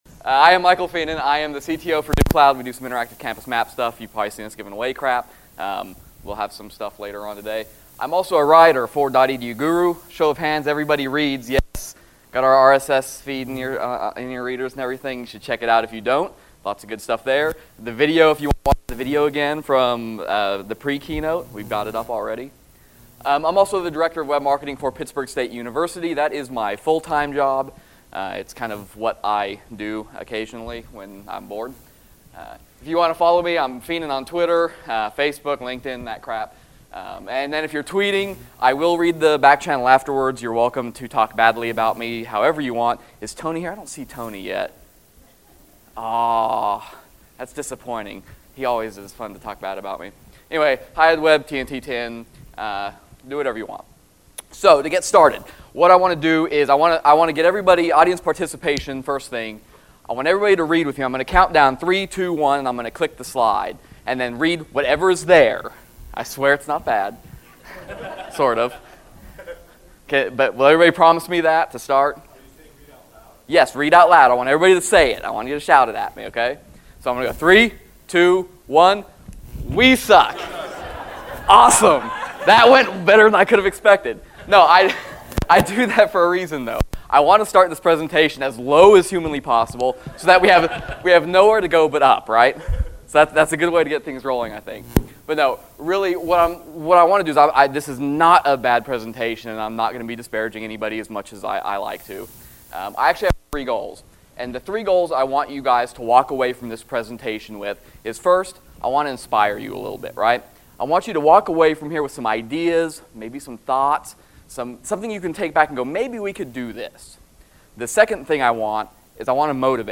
Session Details - HighEdWeb 2010: The National Conference for Higher Education Web Professionals